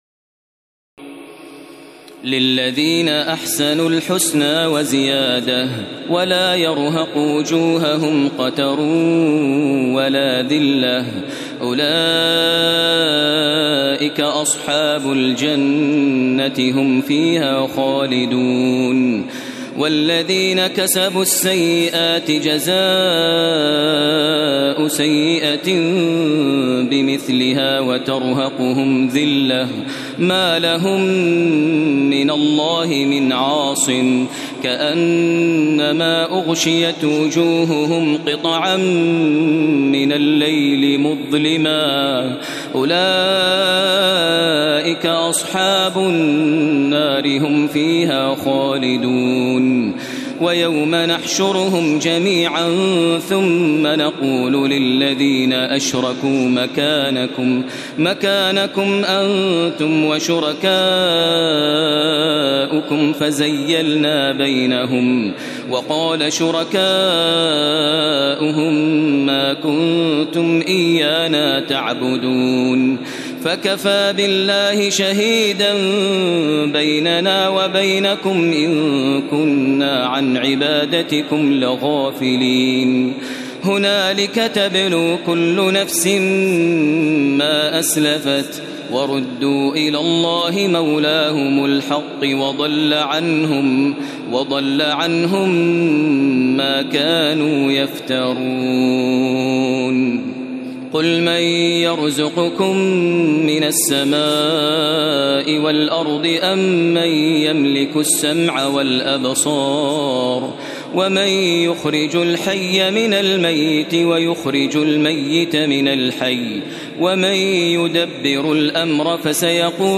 تراويح الليلة العاشرة رمضان 1432هـ من سورة يونس (26-109) Taraweeh 10 st night Ramadan 1432H from Surah Yunus > تراويح الحرم المكي عام 1432 🕋 > التراويح - تلاوات الحرمين